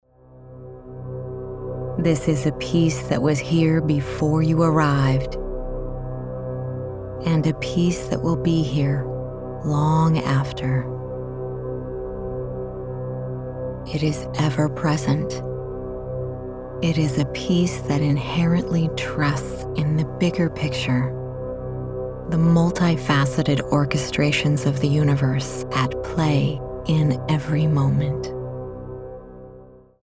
Sample Peaceful Meditation.mp3